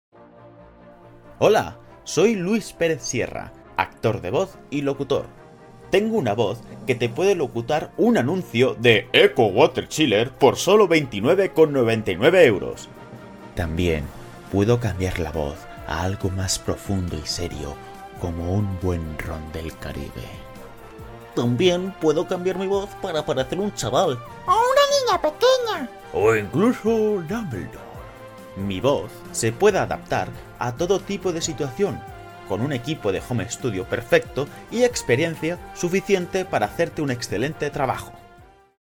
Demo de voz
Castellano neutro
Young Adult